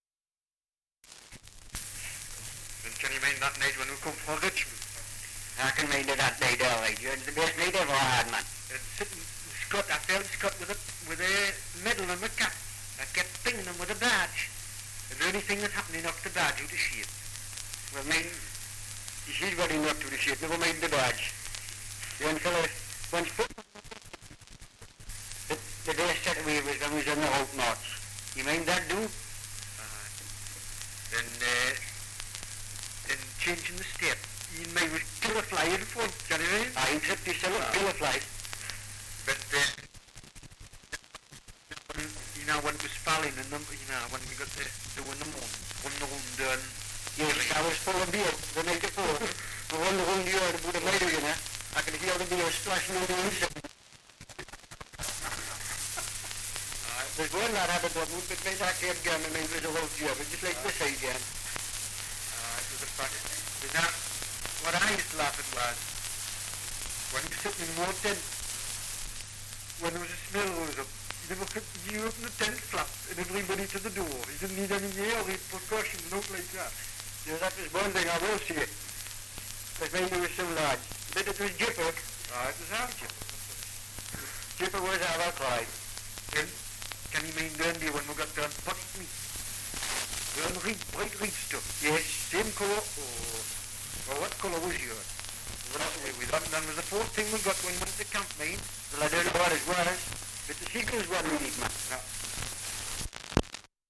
Dialect recording in Belford, Northumberland
Only second band of Side 1 digitised; copy of first band possibly available at C908/35 C10. (British Library reference number)
78 r.p.m., cellulose nitrate on aluminium